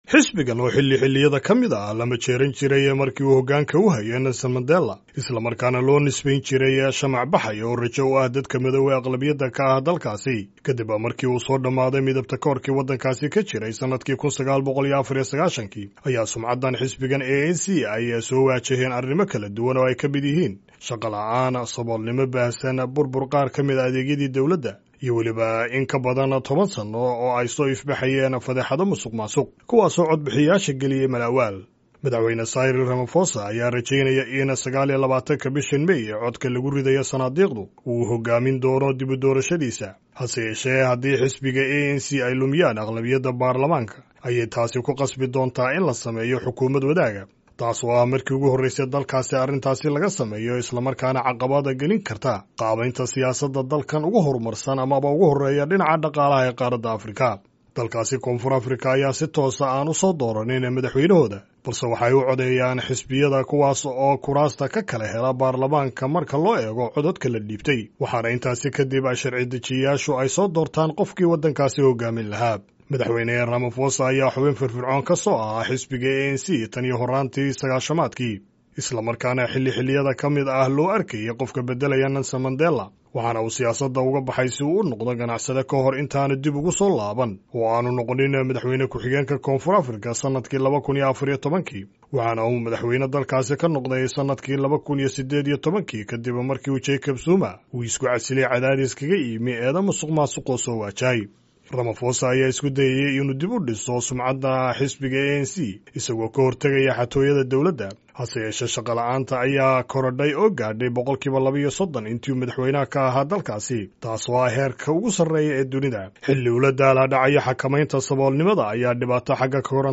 Warbixin ay arintan ka qortay wakaalada wararka ee Associated Press